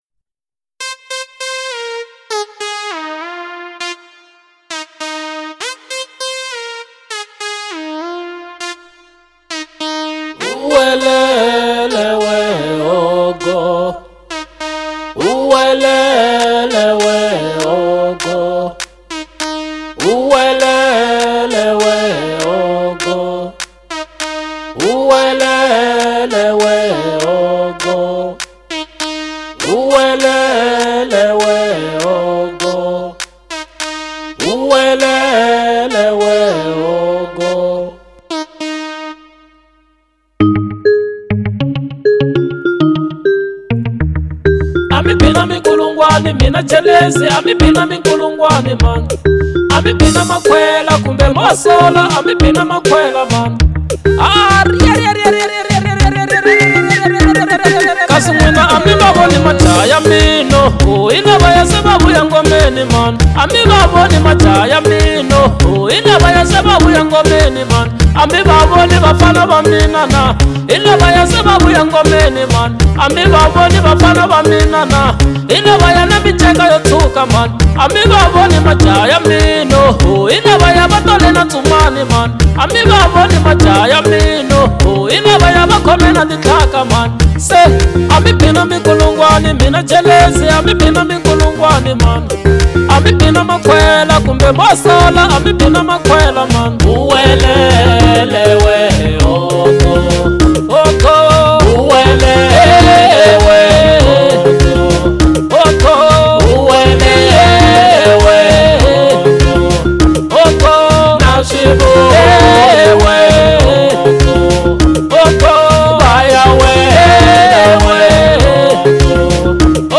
05:46 Genre : Xitsonga Size